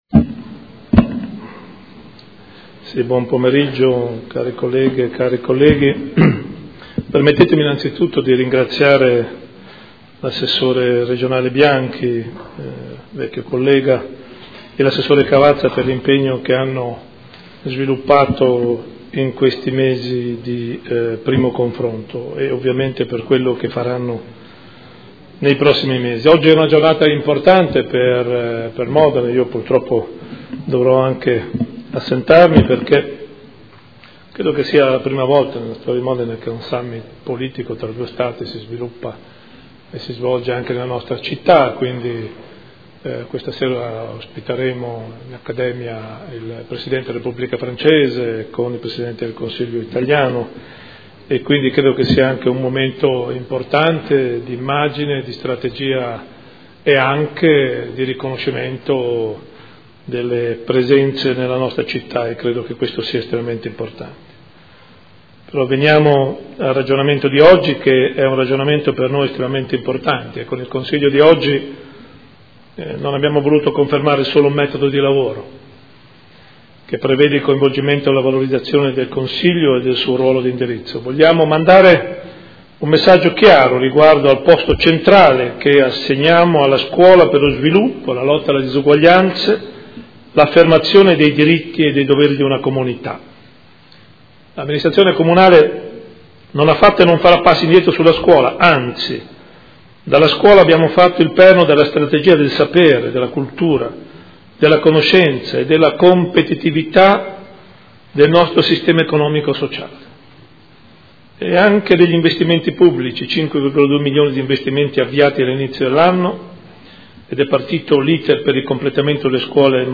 Seduta del 17/09/2015. Intervento del Sindaco sul progetto "Educare Insieme", piano della buona scuola a Modena